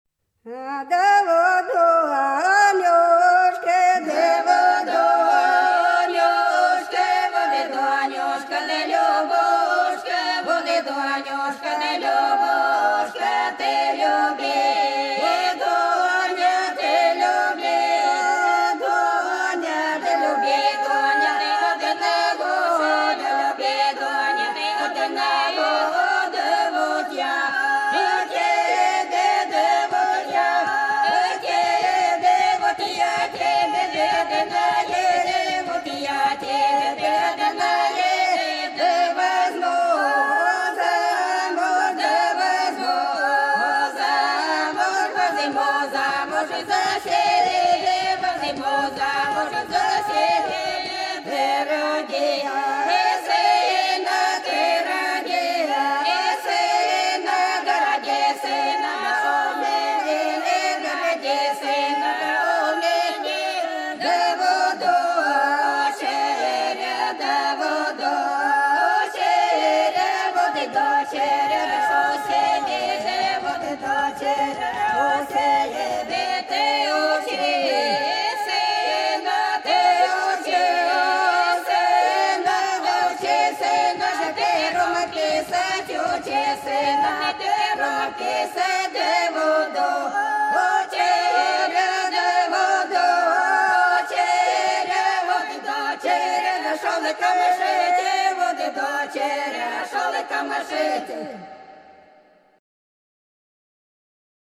Долина была широкая (Поют народные исполнители села Нижняя Покровка Белгородской области) Вот Донюшка - любушка - плясовая